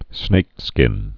(snākskĭn)